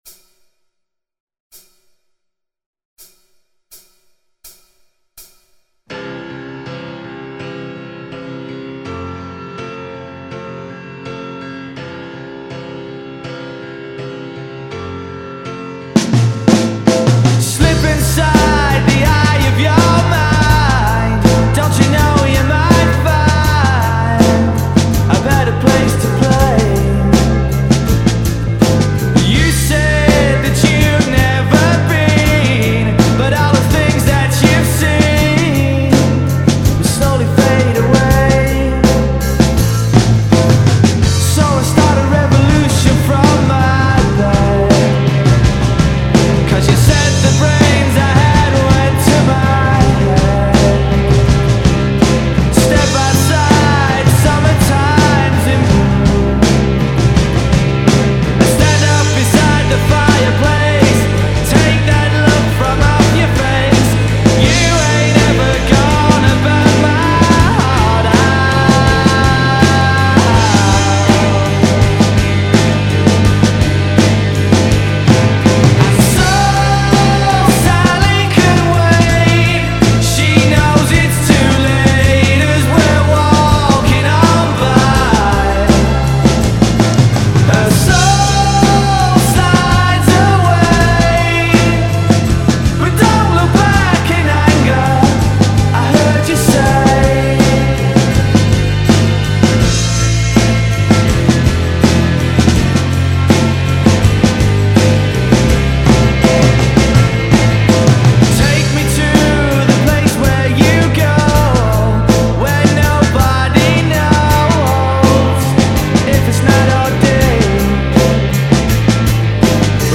Tempat Download Backing Track